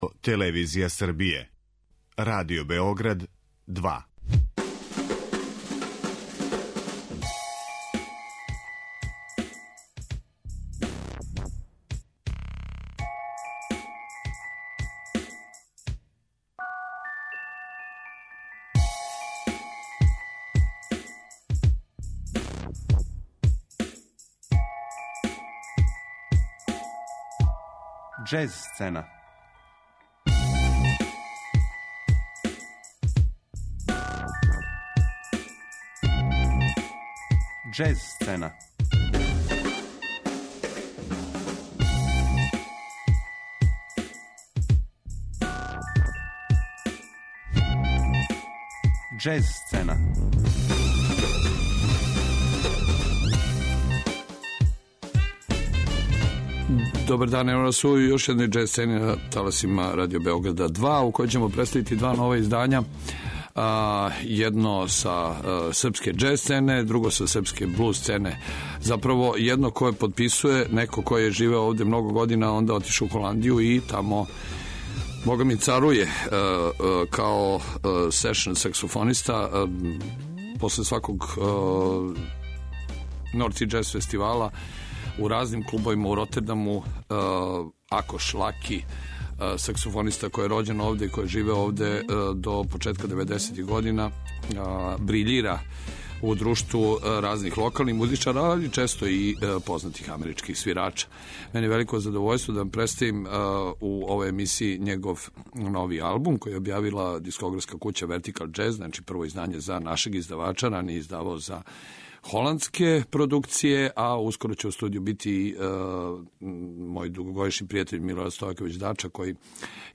Представићемо два нова издања српских џез и блуз уметника.